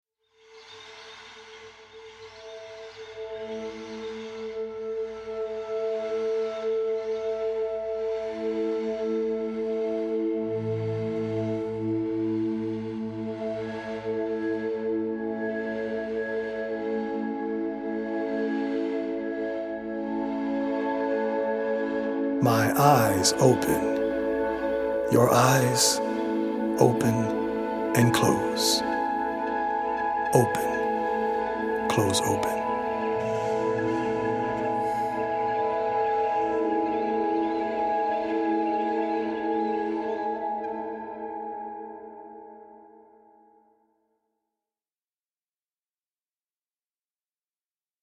poetic recordings
healing Solfeggio frequency music